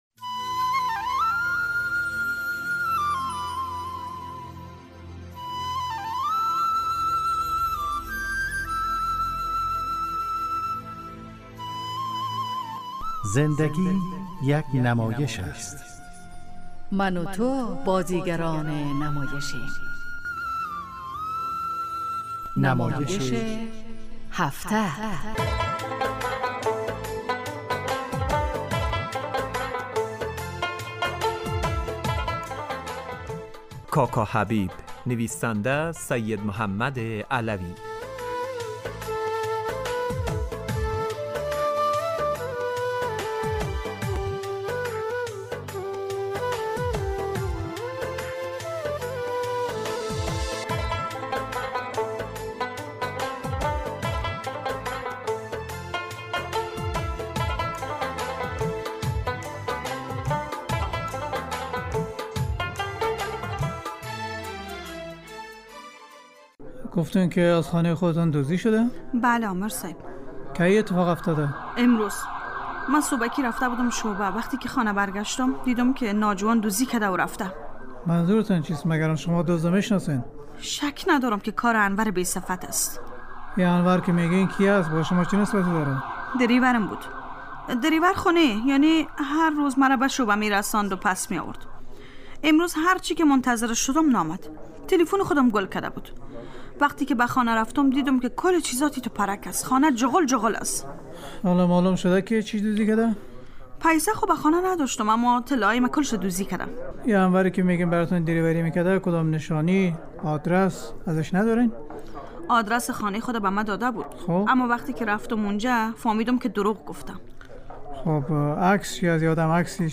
نمایش هفته